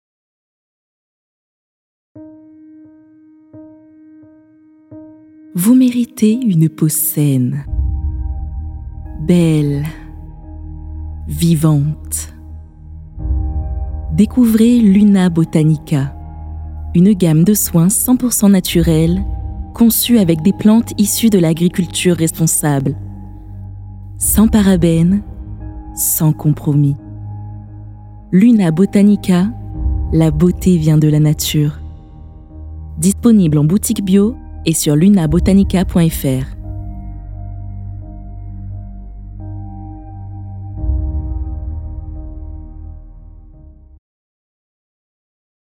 extrait pub automobile
comédienne voix off